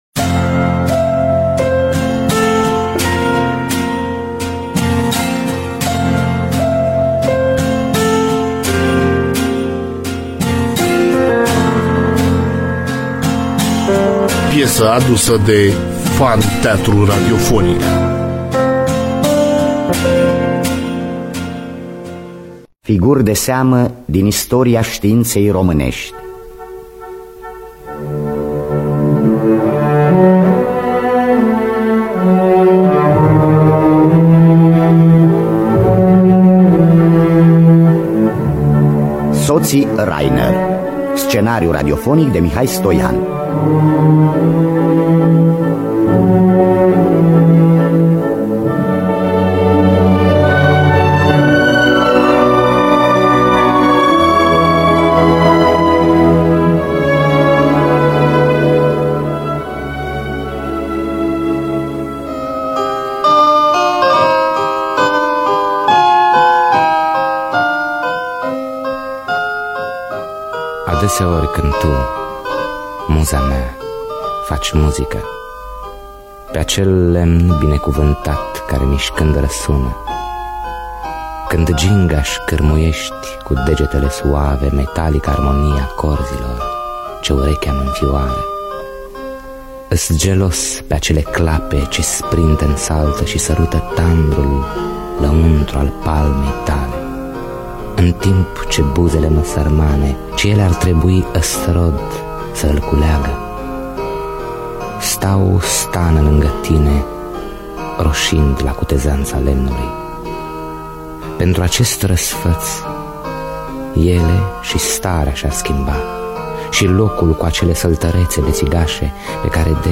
Biografii, Memorii: Sotii Rainer (1983) – Teatru Radiofonic Online